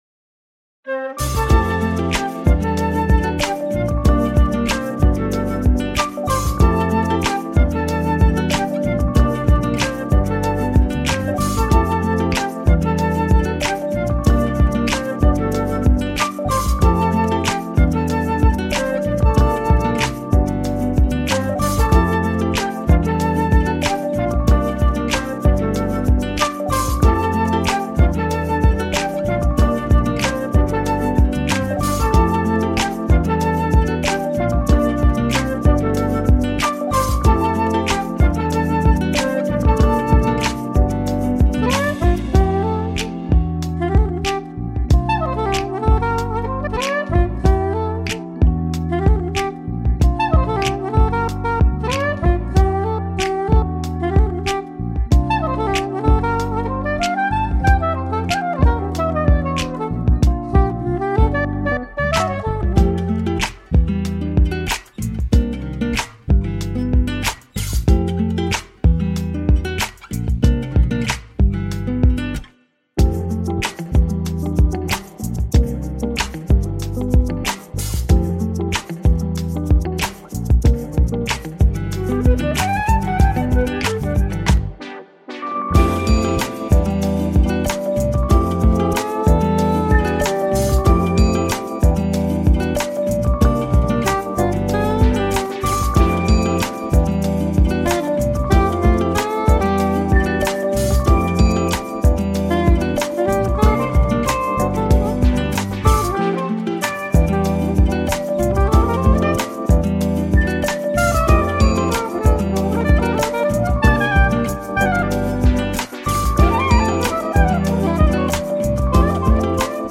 > NU DISCO・BALEARIC・NU JAZZ・CROSSOVER・REGGAE
【7"INCH】(レコード)